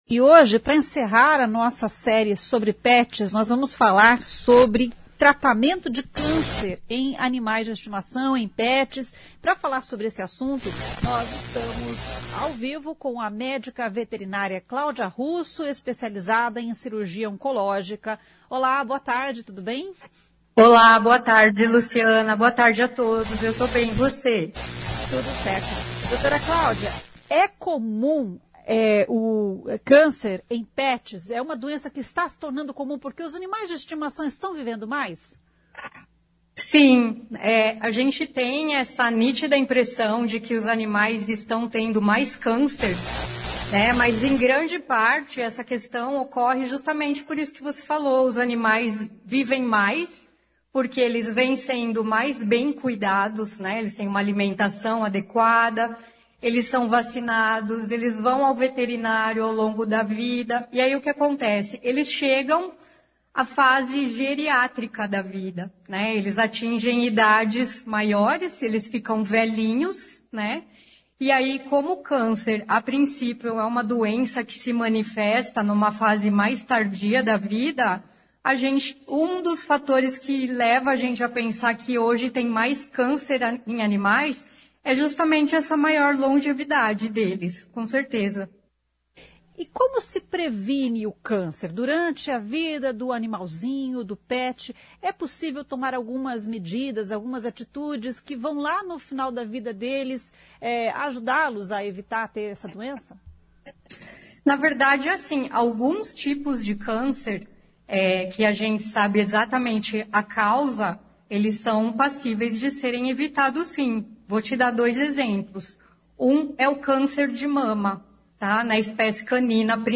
Nesta conversa